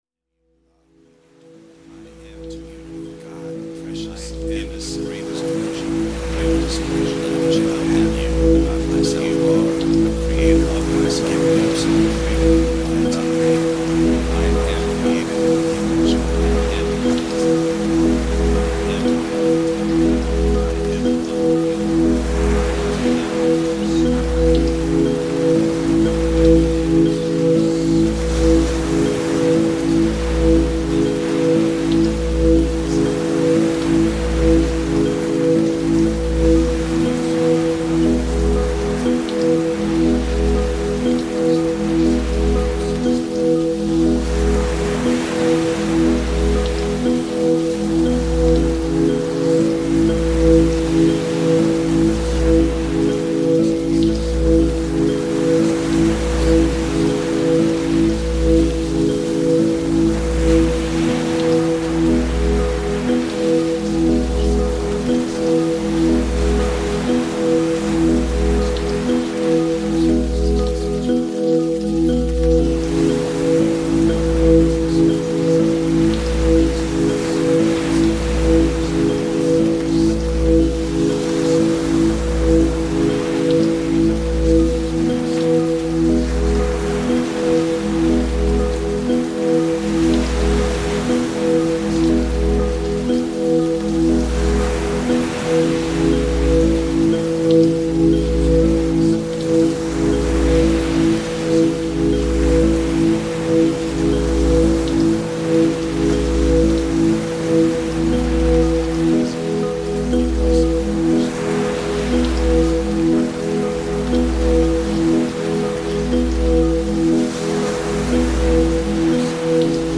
2 subliminal tracks.
With Theta Waves
You hear it as a rhythmic pulsating steady low tone.